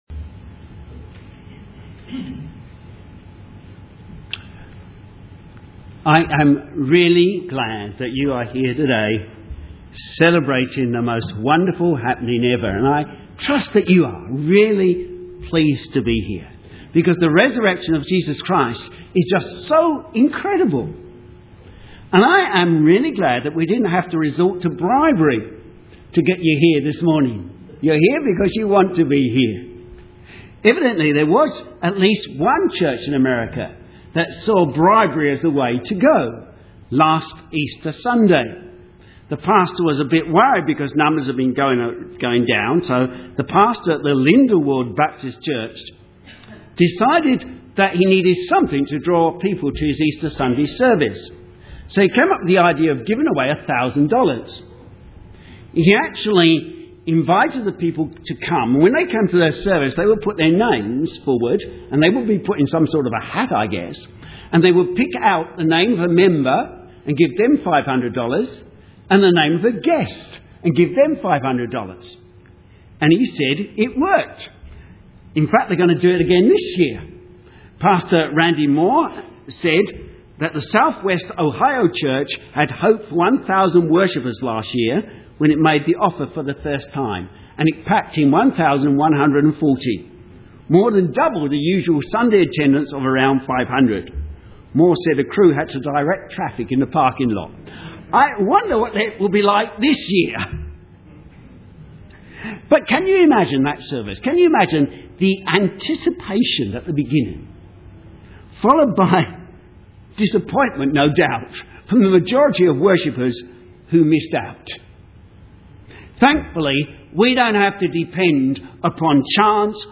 Sermon
Easter Subday The Weekend That Changed The World Matthew 28:1-20 Synopsis A short and simple Easter Sunday sermon Keywords Change.